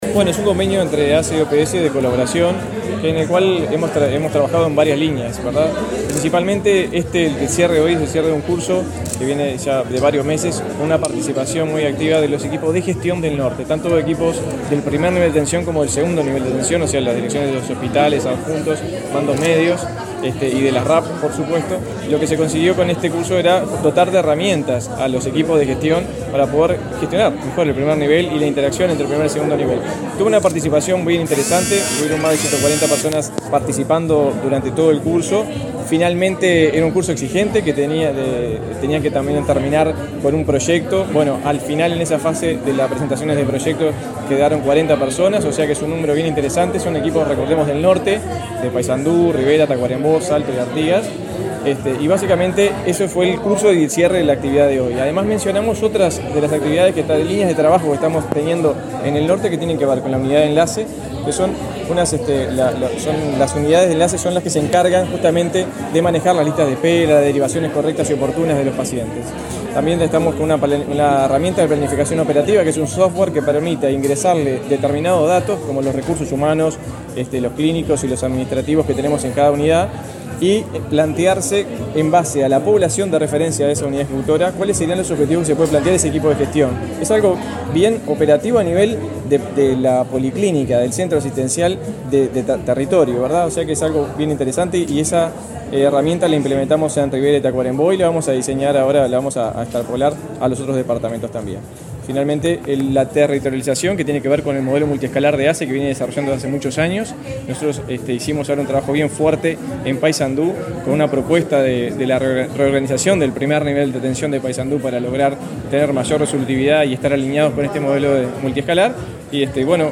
Declaraciones del director de Primer Nivel de Atención de ASSE
El director del Primer Nivel de Atención de la Administración de los Servicios de Salud del Estado (ASSE), Daniel Strozzi, dialogó con la prensa,